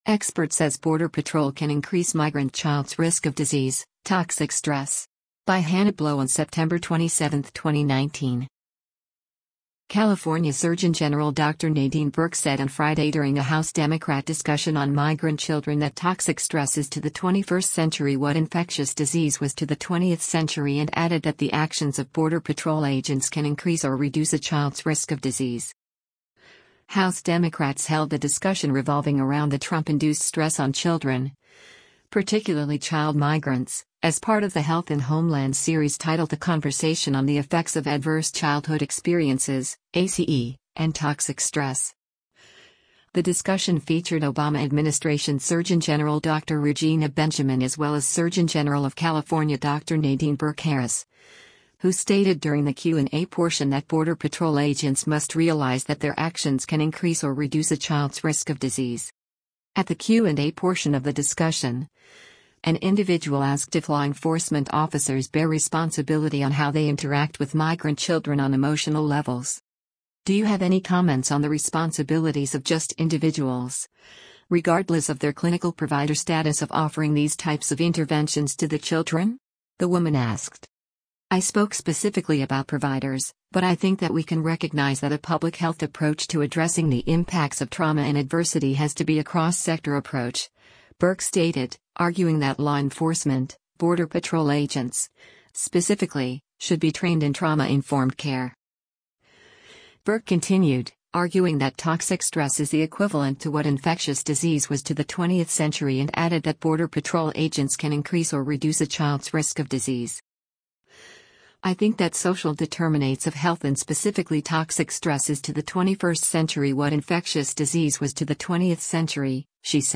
California Surgeon General Dr. Nadine Burke said on Friday during a House Democrat discussion on migrant children that toxic stress “is to the 21st century what infectious disease was to the 20th century” and added that the actions of Border Patrol agents can increase or reduce a child’s risk of disease.
At the Q&A portion of the discussion, an individual asked if law enforcement officers bear responsibility on how they interact with migrant children on emotional levels.